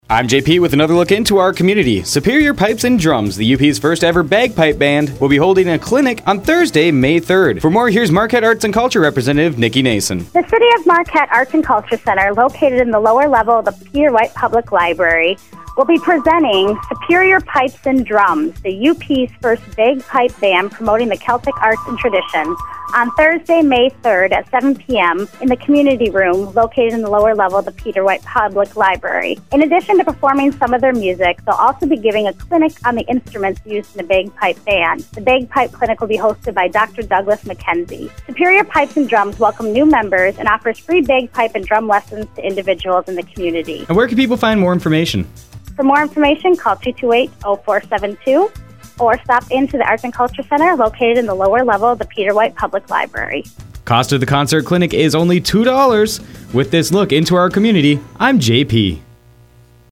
Interview – Interviewee – Subject of Interview